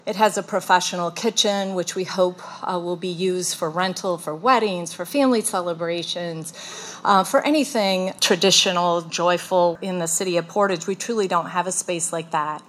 Portage’s brand new Senior Center is now open after ribbon-cutting ceremonies on Friday, May 20.
Portage Mayor Patricia Randall says while the focus will be on seniors, it won’t exclusively be for them.